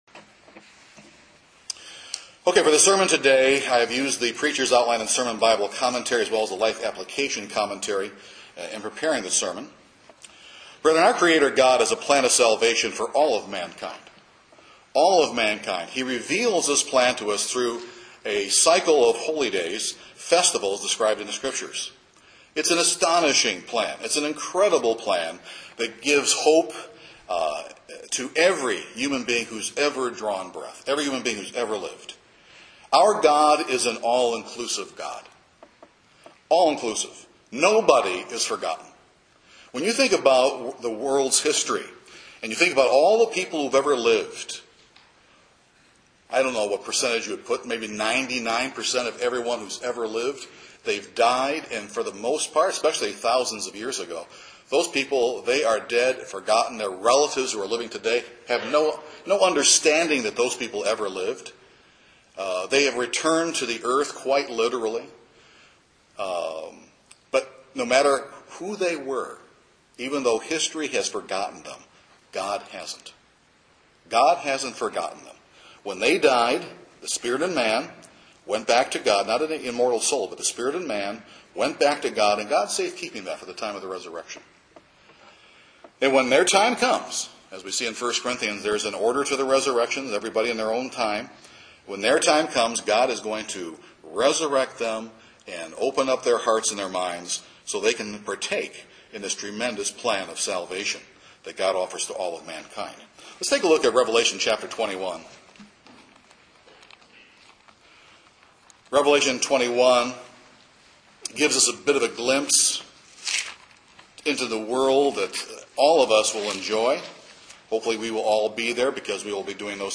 This sermon will examine three myths that Satan would have us believe and that would rob us of our Passover joy.